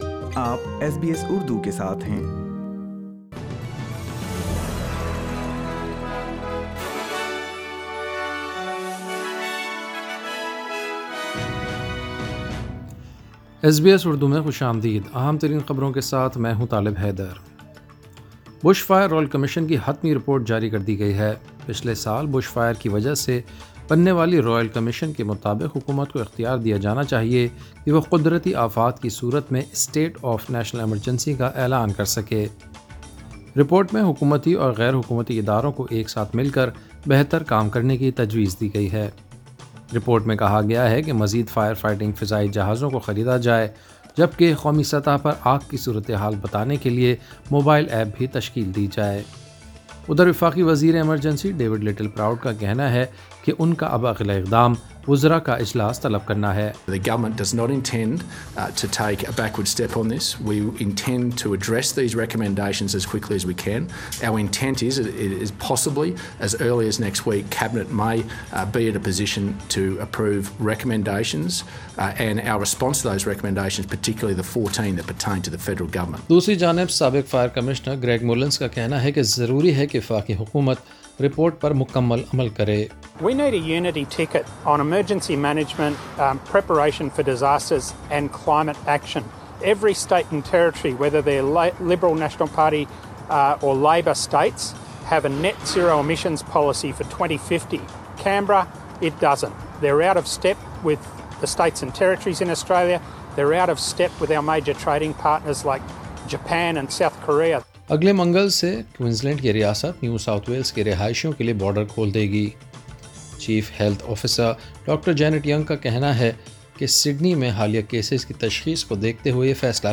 ایس بی ایس اردو خبریں 30 اکتوبر 2020